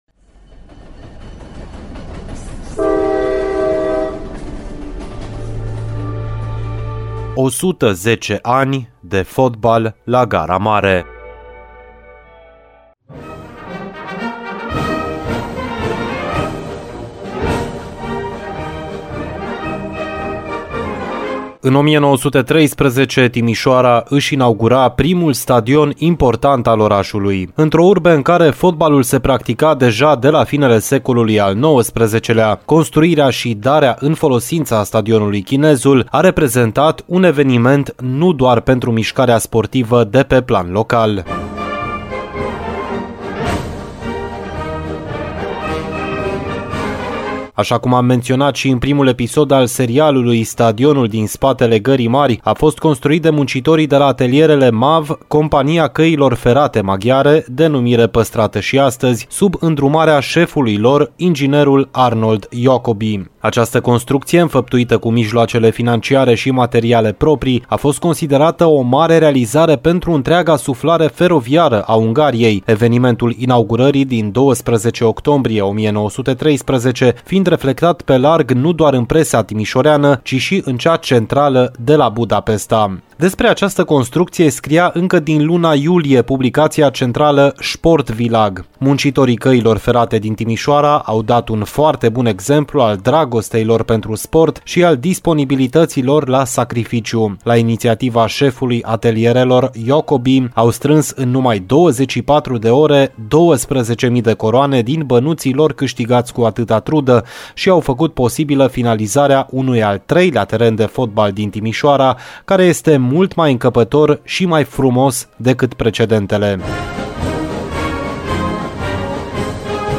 a fost difuzat în ediția de sâmbătă a emisiunii Arena Radio.